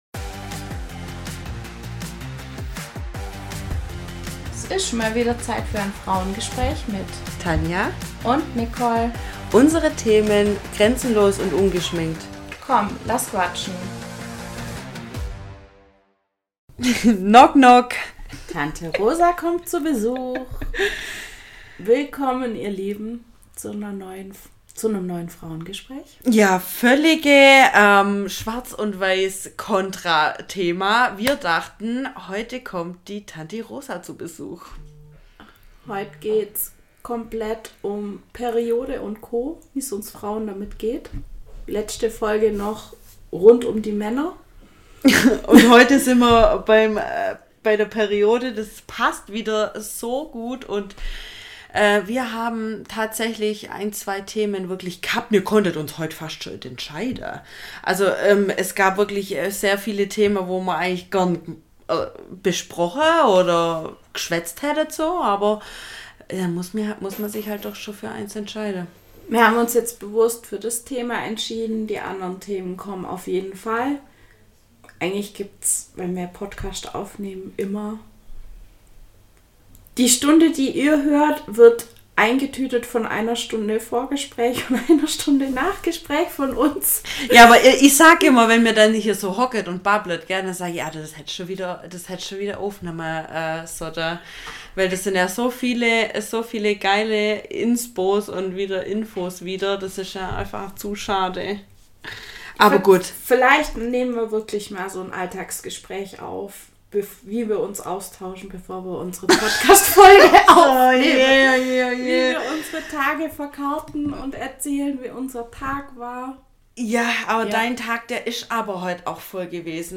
Es ist mal wieder Zeit für ein FRAUENGESPRÄCH…